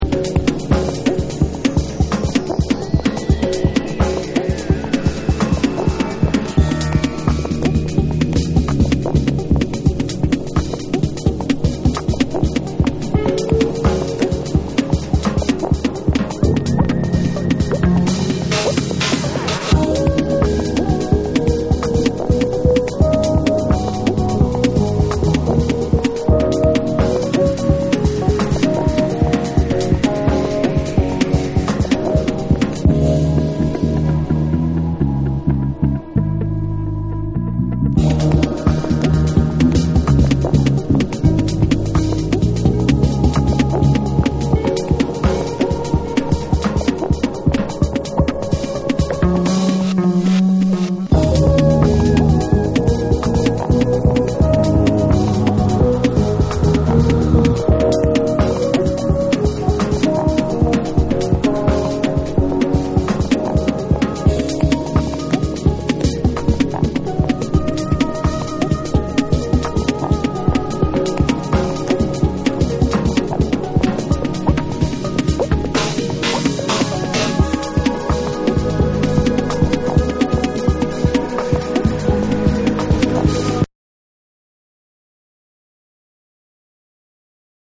REGGAE & DUB / SOUL & FUNK & JAZZ & etc